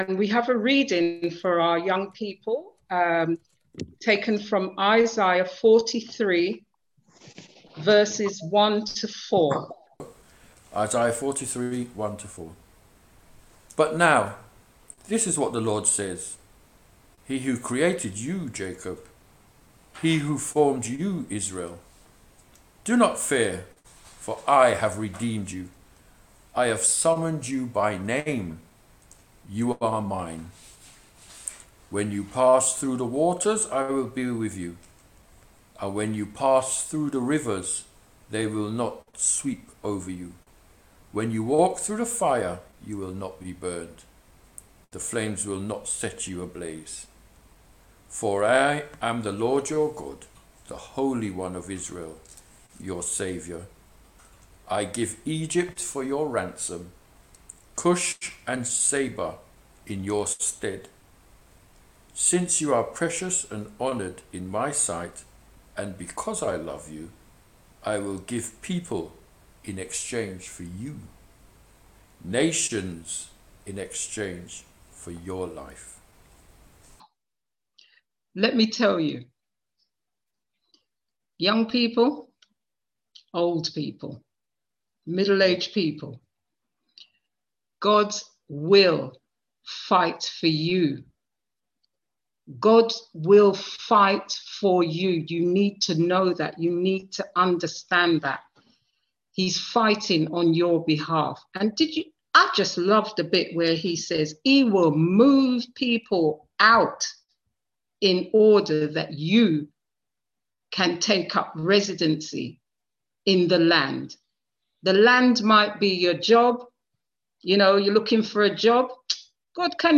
on 2021-05-26 - Redeeming Love Children & Youth Prayer Service 6.2.21